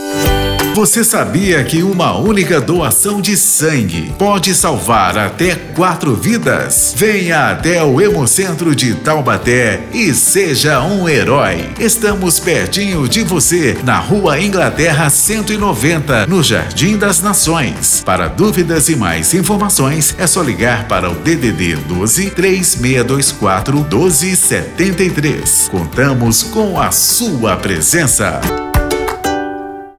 Você também pode ajudar a divulgar a doação de sangue usando nossos spots para rádio ou carros de som: